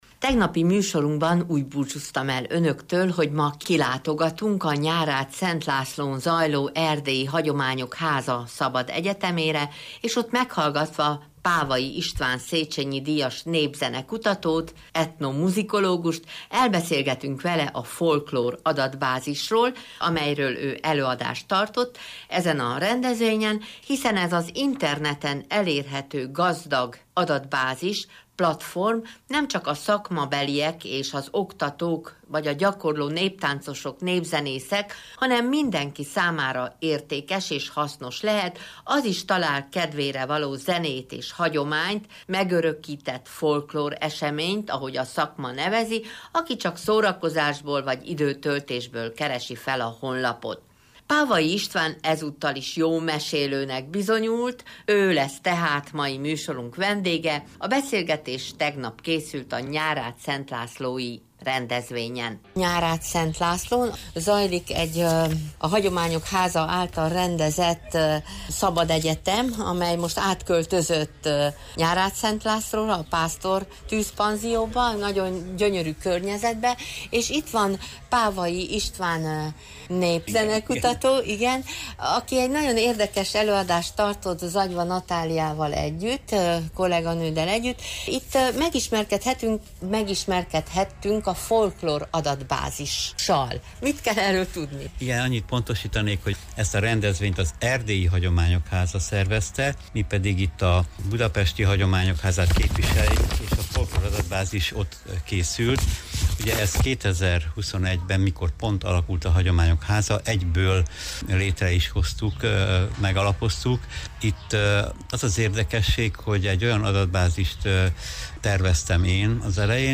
Mi is ott voltunk, és felvételt készítettünk az előadóval, ezt a beszélgetést június 22-ei műsorunkban hallgathatták meg.